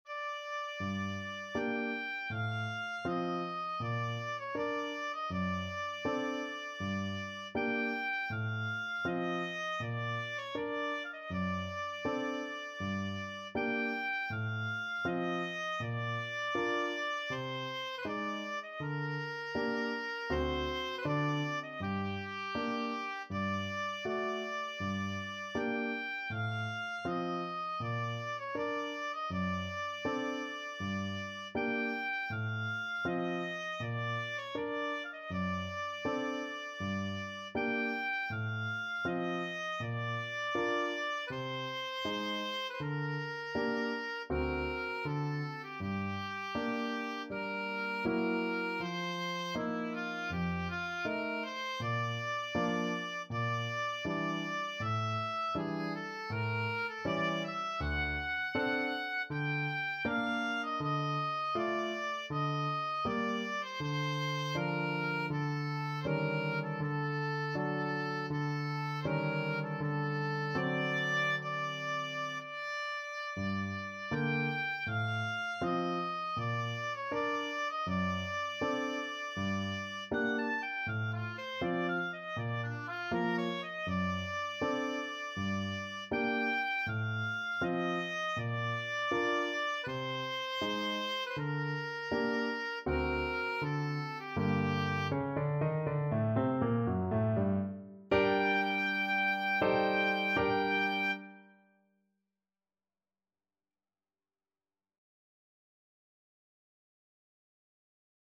Oboe
G minor (Sounding Pitch) (View more G minor Music for Oboe )
4/4 (View more 4/4 Music)
Andante = c.80
Classical (View more Classical Oboe Music)